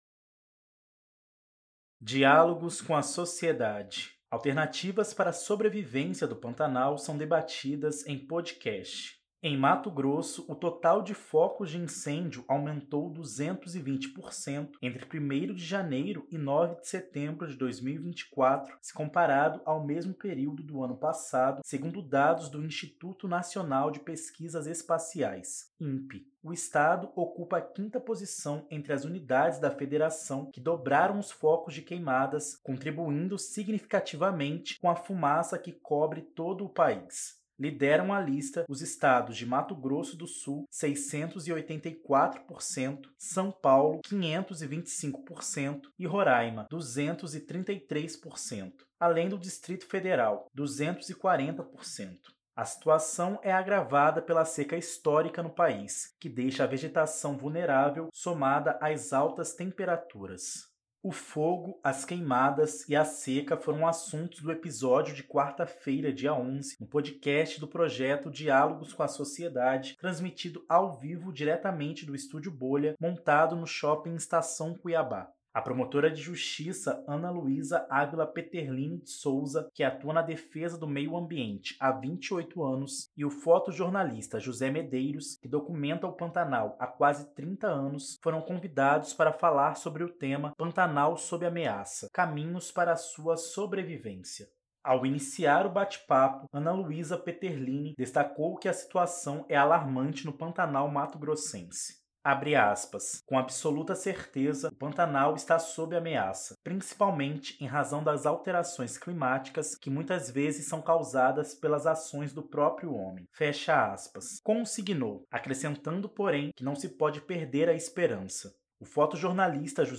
O fogo, as queimadas e a seca foram assuntos do episódio de quarta-feira (11) no podcast do projeto Diálogos com a Sociedade, transmitido ao vivo diretamente do estúdio “bolha” montado no Shopping Estação Cuiabá.